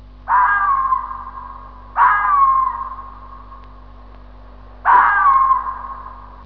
qui per ascoltare il verso della volpe
volpe.wav